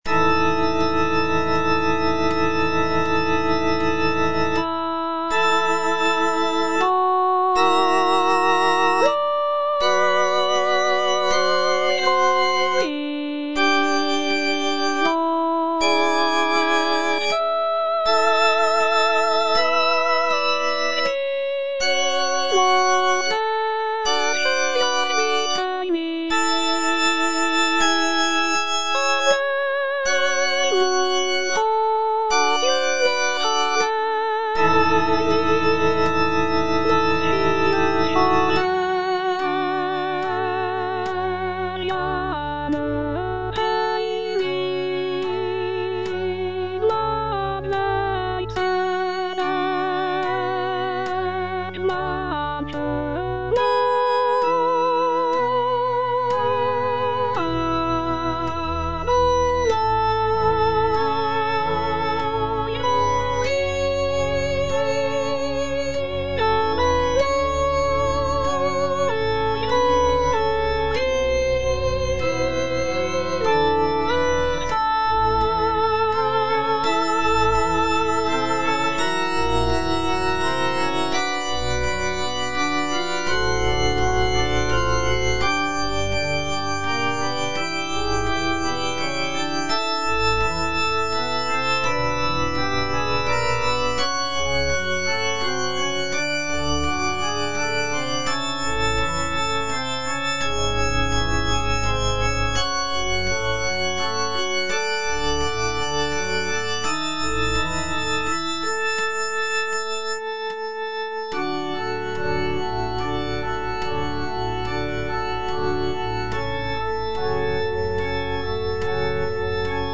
tenor II) (Voice with metronome